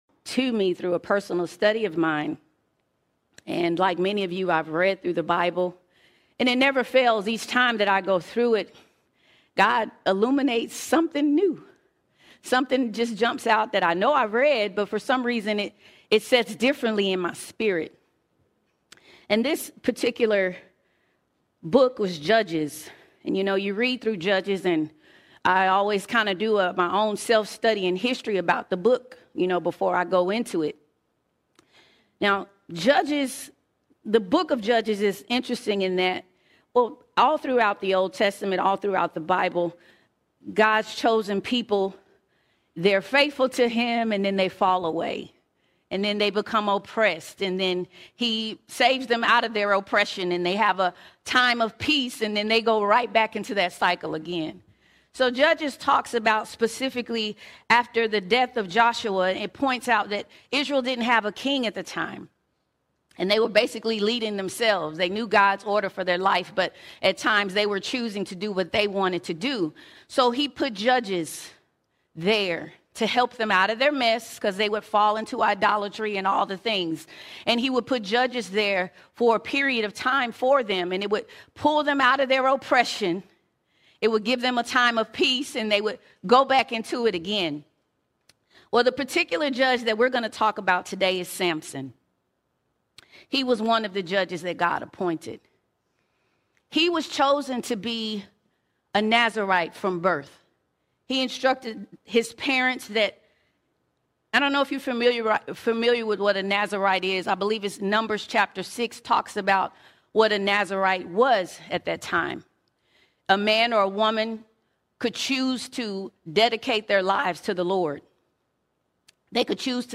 9 February 2026 Series: Sunday Sermons All Sermons More In Death More In Death Like samson God has called us to complete obedience, however sometimes we have parts of our life where we have selective obedience.